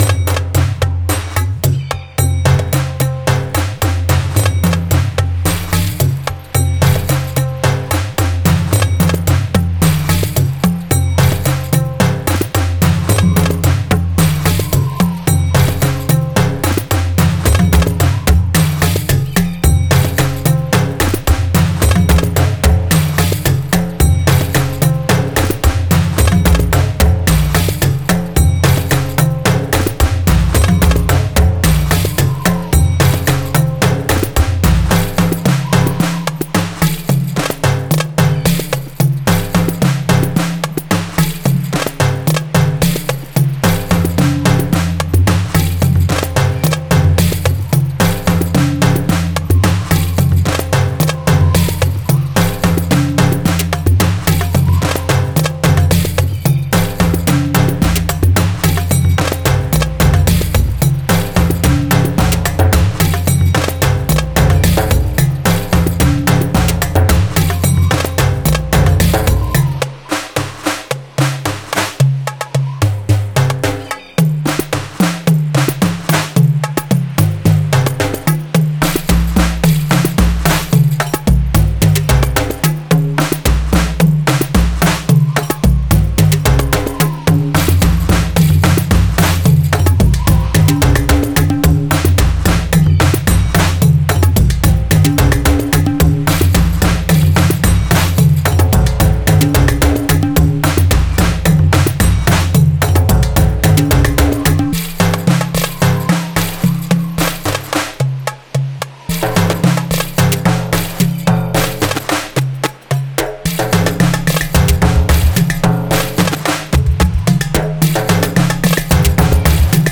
Genre:India
GavalとTalaのループは催眠的なリズムパターンで脈打つような部族的ビートを加えます。
Uduは滑らかで丸みのある低音テクスチャーを提供し、リズムをしっかりと支えます。
18 Tabla Loops